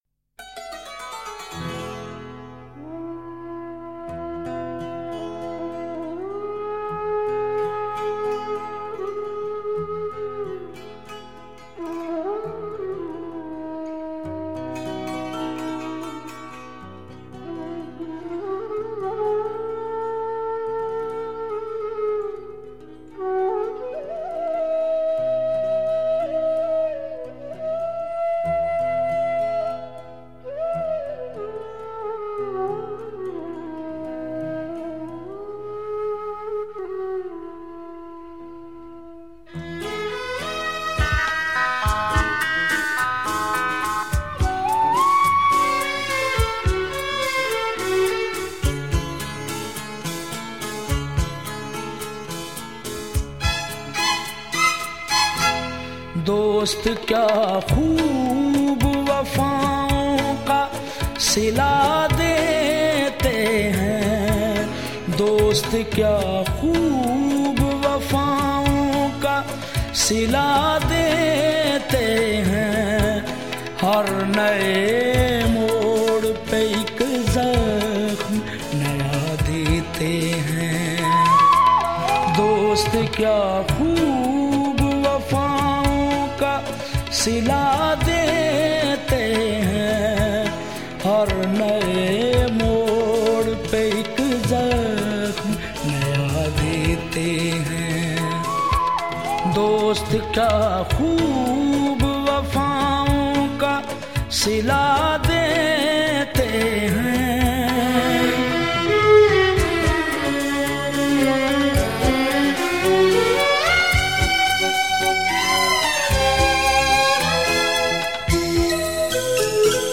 a traditional style of qawwali